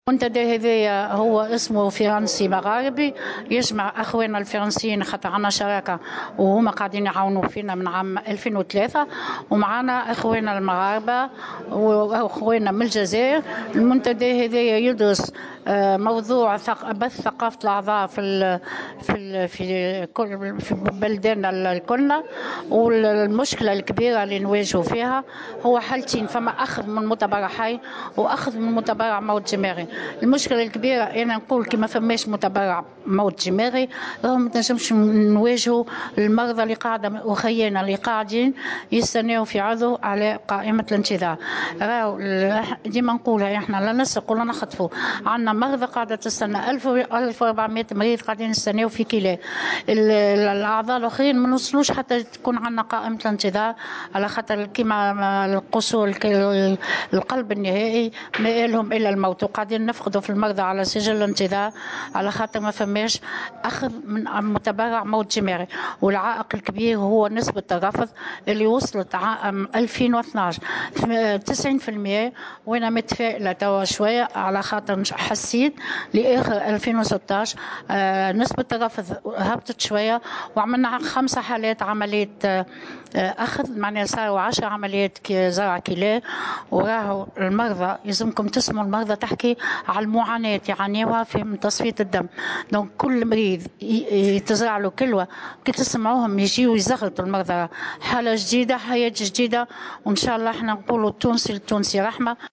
وأشارت في تصريح لـ"الجوهرة أف أم" على هامش المنتدى السابع المغاربي الفرنسي حول" دور وسائل الإعلام والمجتمع المدني والعلماء في النهوض بزراعة الأعضاء" إلى الصعوبات التي يواجهها المرضى، في ظل غياب ثقافة التبرع بالأعضاء.